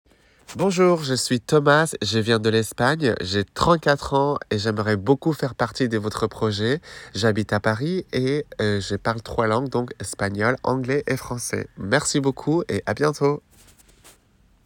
Voix off
Présentation français